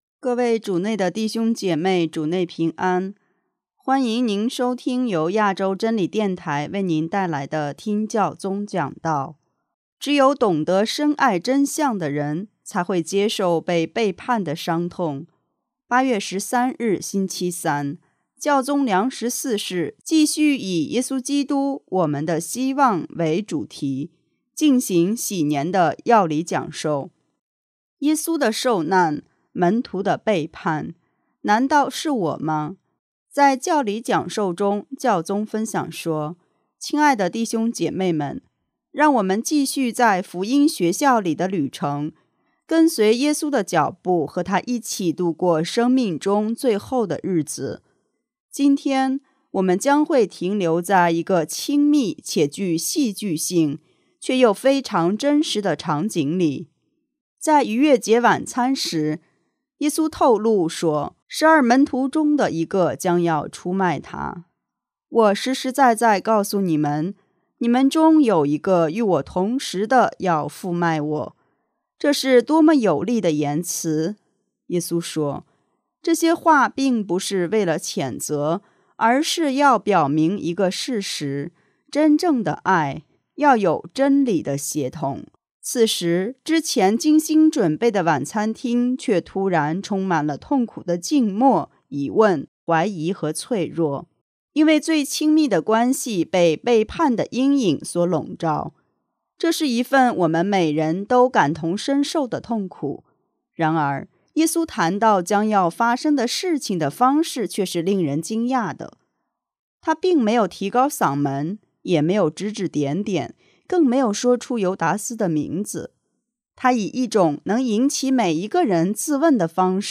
【听教宗讲道】| 只有懂得深爱真相的人，才会接受被背叛的伤痛
813日，星期三，教宗良十四世在圣保禄六世大厅举行公开接见活动，继续以“耶稣基督——我们的希望为主题进行禧年的教理讲授。